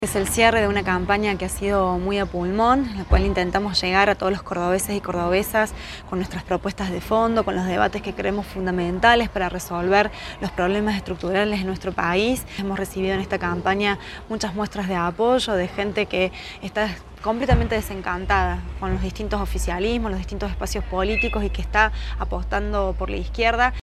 La legisladora del MST lo indicó tras sufragar en una escuela de barrio Poeta Lugones de la capital cordobesa.